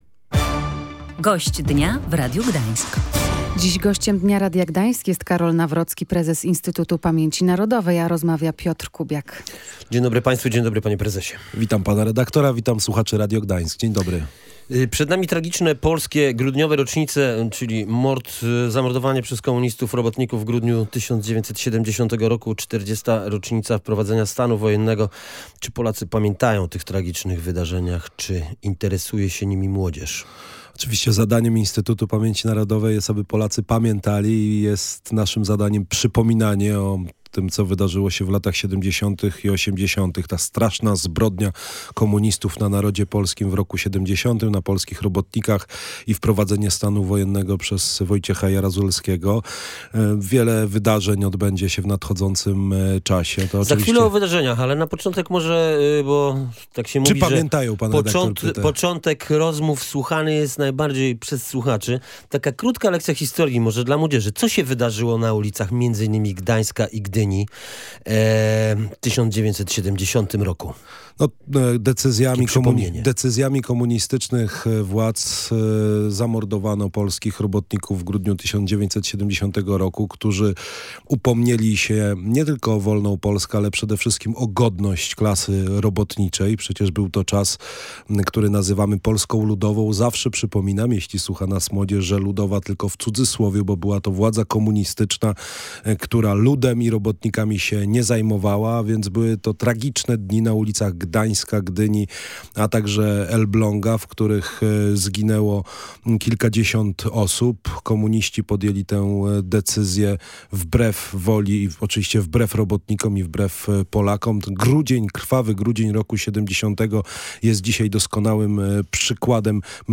Wszyscy Polacy w obliczu sytuacji na granicy z Białorusią powinni być teraz razem – mówił w Radiu Gdańsk prezes Instytutu Pamięci Narodowej dr Karol Nawrocki. Jego zdaniem nieuprawnione są ataki na służby mundurowe, które bronią naszego bezpieczeństwa.